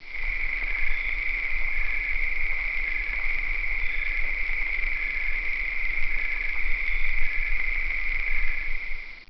cricket-2_converted.wav